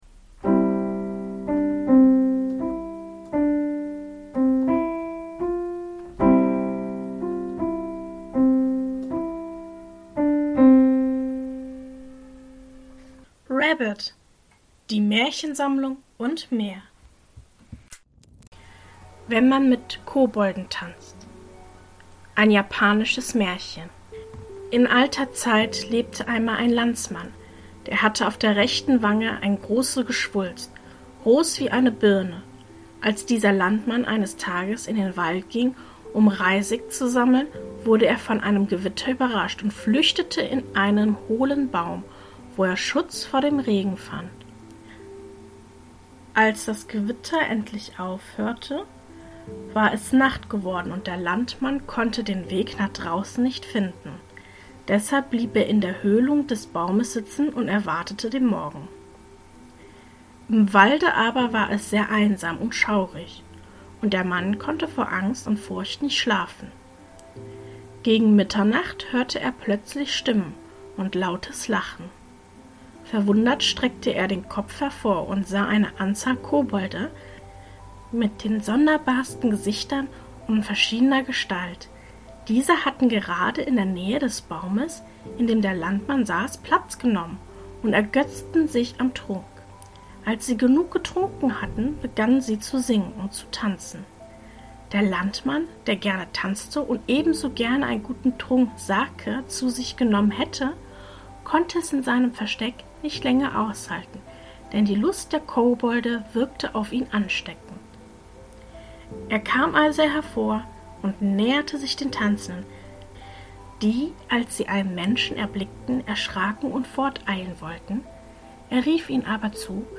In der heutigen Folge lese ich Folgendes vor: 1. Wenn man mit Kobolden Tanzt! 2.